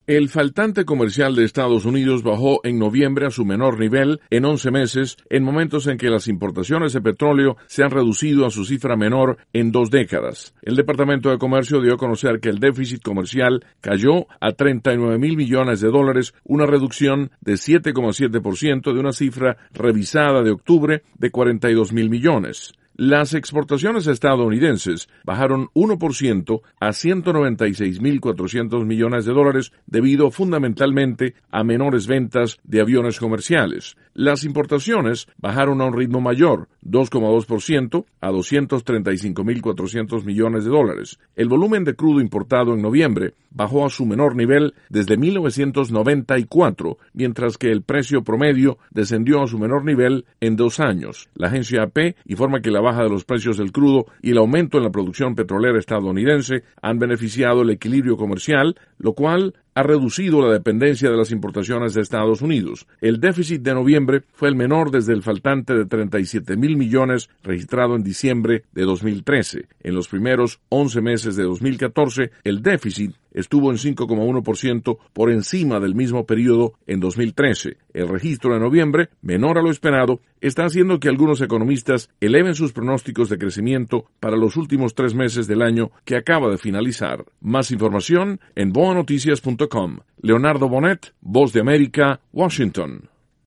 informa desde Washington.